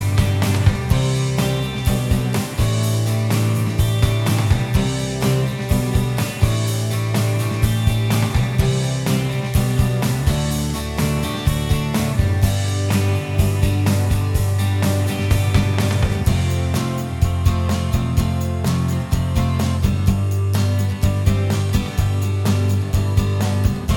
Minus Lead And Solo Indie / Alternative 3:58 Buy £1.50